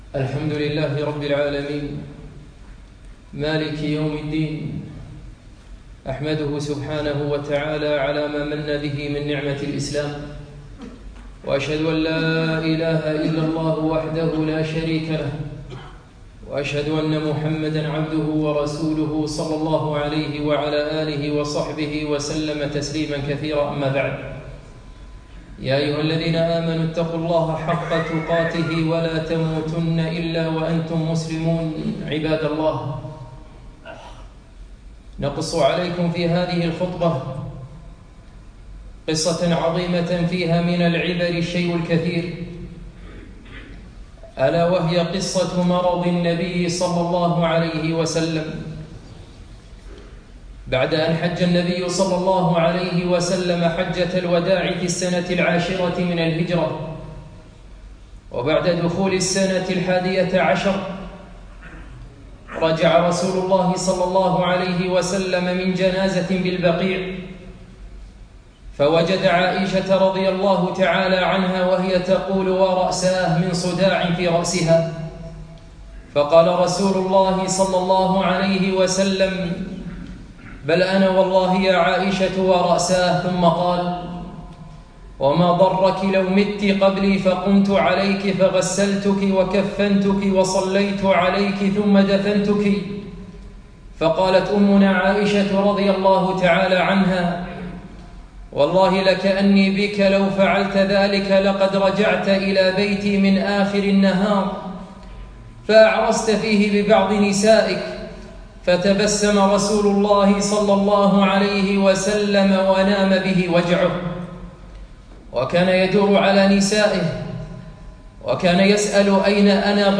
خطبة - قصة مرض النبي ﷺ